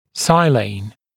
[ˈsʌɪleɪn][ˈсайлэйн]силан (хим.)